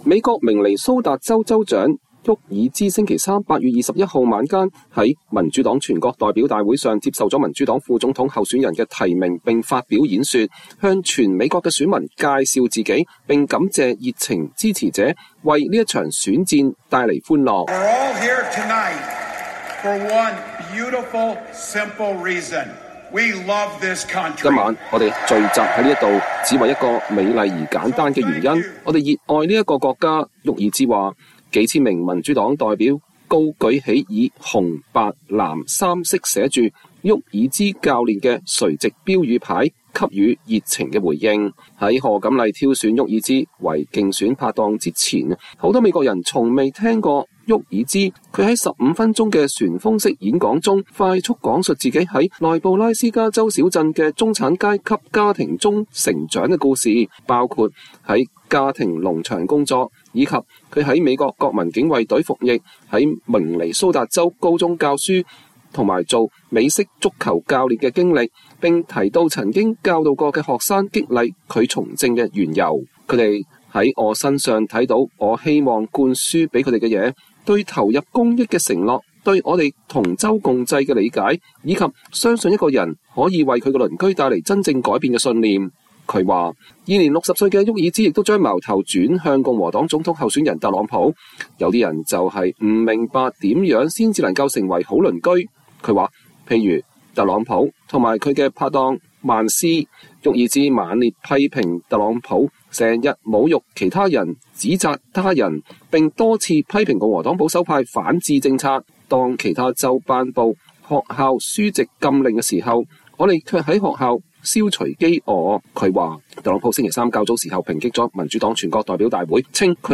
沃爾茲發表演說接受民主黨副總統候選人提名
美國明尼蘇達州州長蒂姆·沃爾茲(Tim Walz)星期三(8月21日)晚在民主黨全國代表大會上接受了民主黨副總統候選人的提名並發表了演說，向全美國的選民介紹自己，並感謝熱情支持者為這場選戰“帶來了歡樂”。